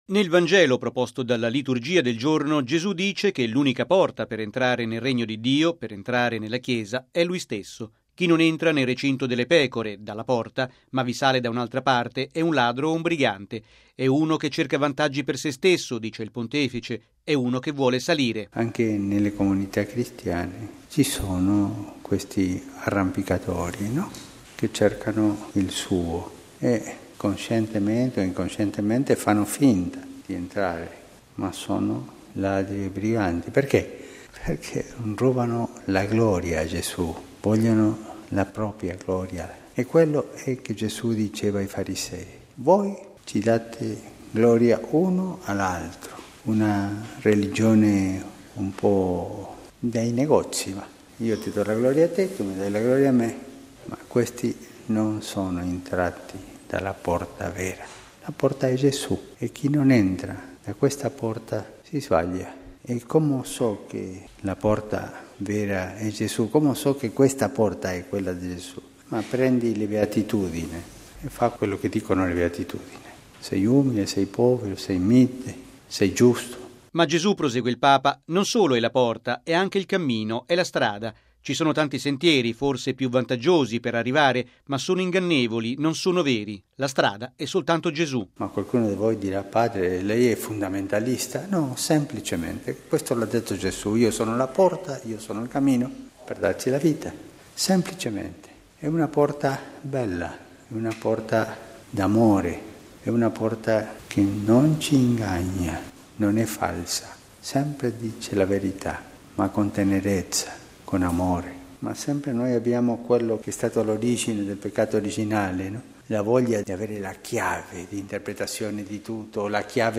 ◊   Il Vangelo del Buon Pastore con Gesù che si definisce “la porta delle pecore” è stato al centro dell’omelia del Papa, stamani, nella Messa celebrata nella Cappellina della Domus Sanctae Marthae.